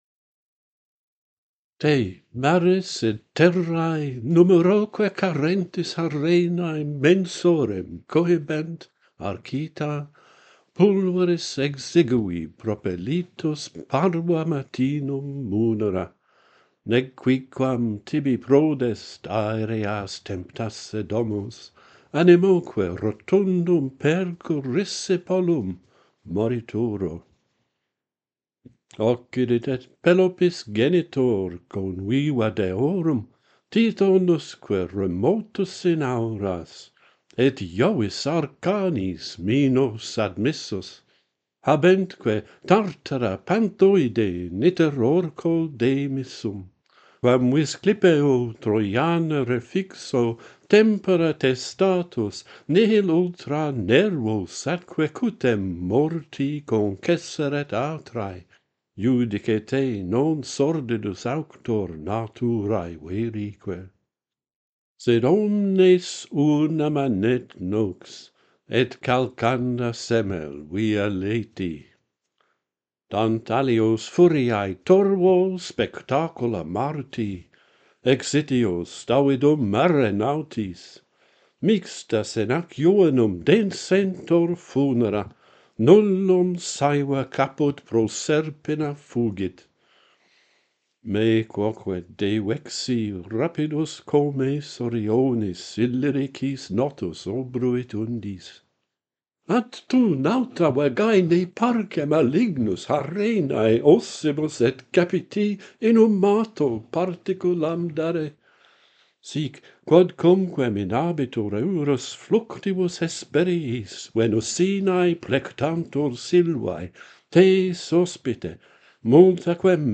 A plea for burial - Pantheon Poets | Latin Poetry Recited and Translated
The unusual metre alternates dactylic hexameters with dactylic tetrameters.